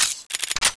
plasmaex_charge_shoot.wav